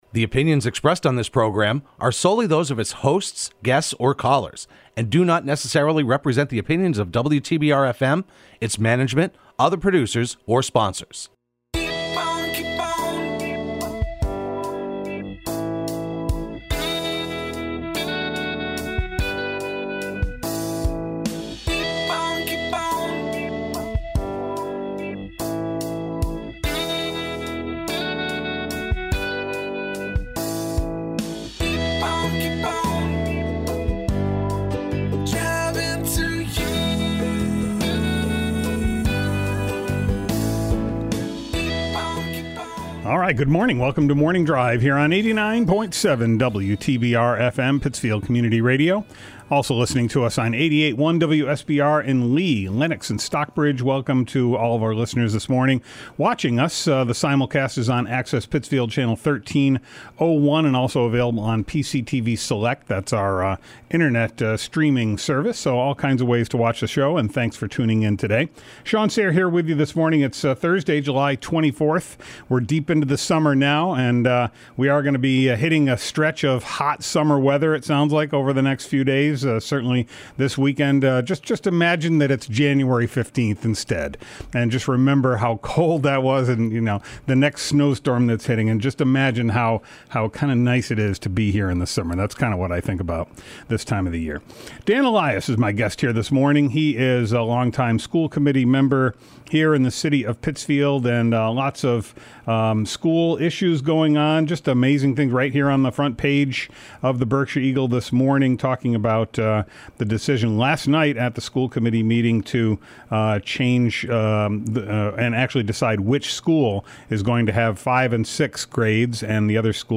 welcomes school committee member Dan Elias to the studio.